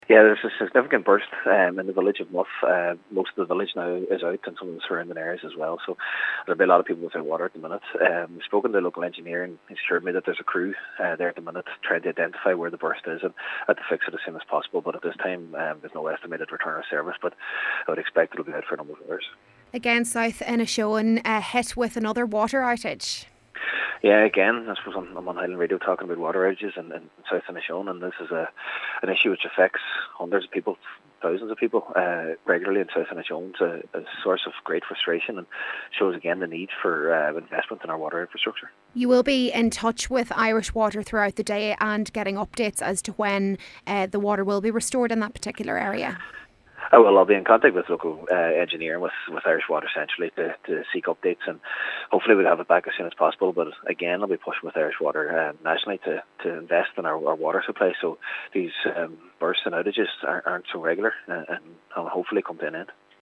Cathaoirleach of the Inishowen Municipal District Cllr. Jack Murray: